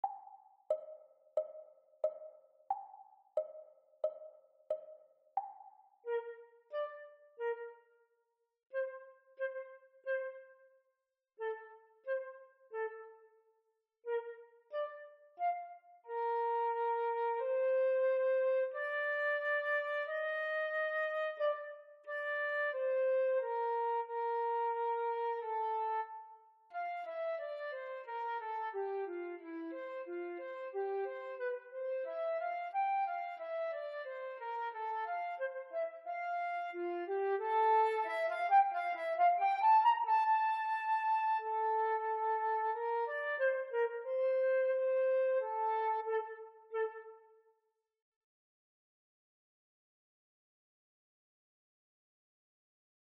Practice Speed (Flute 2 accompaniment)
Flute-Grade-4-Devienne-Practise-Speed.m4a